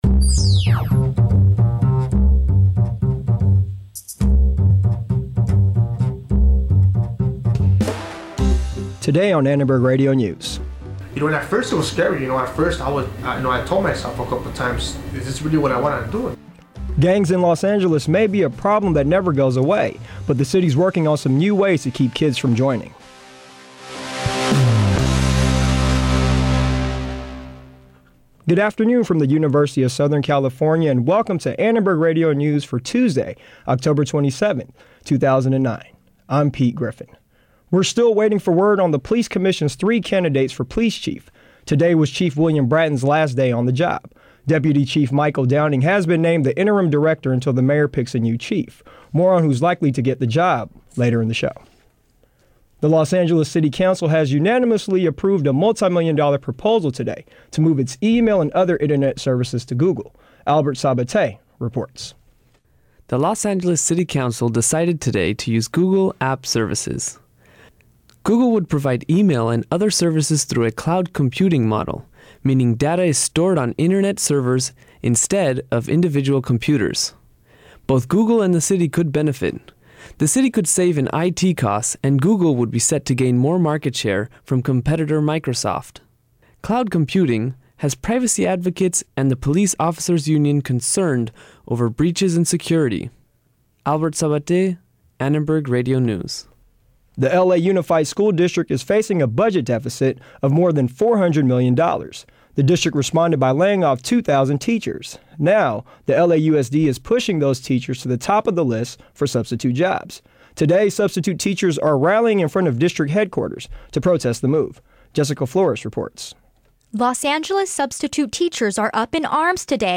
We’ll hear from politicians, gangsters, police and civic leaders about what the new strategies that the city is adopting.